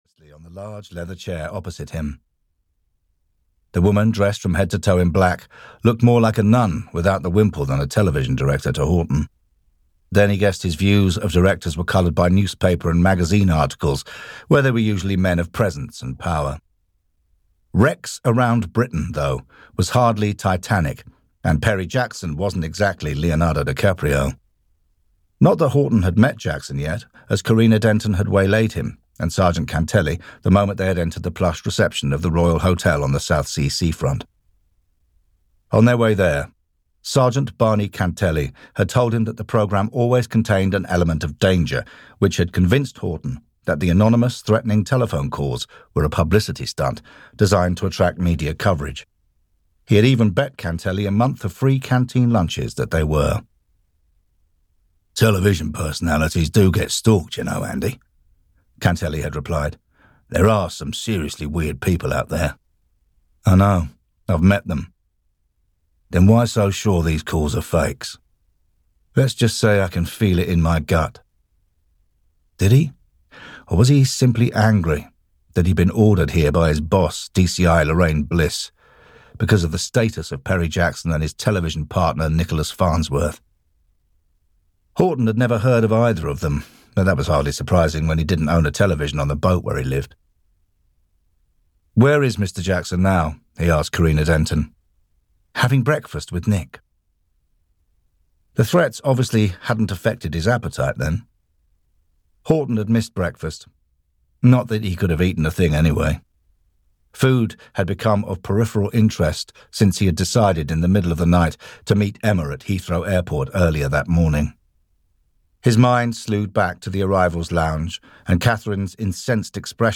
The Royal Hotel Murders (EN) audiokniha
Ukázka z knihy